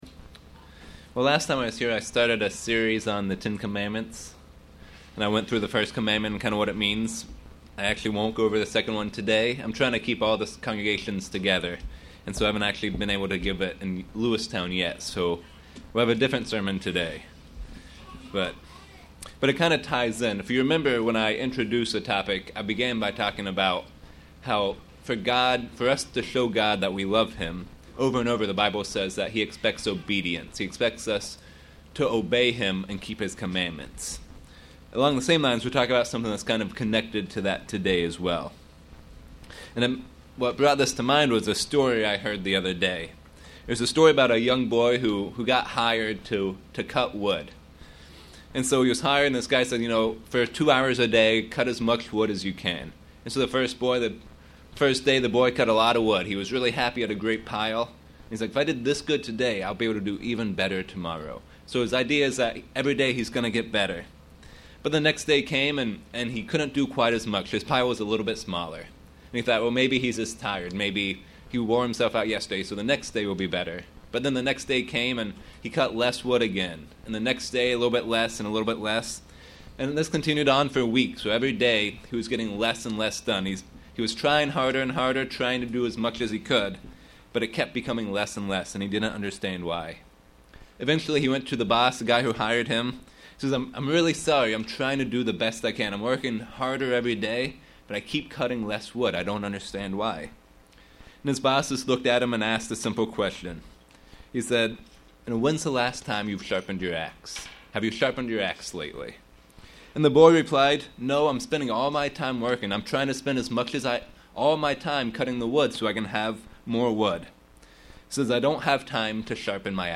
Sermons
Given in York, PA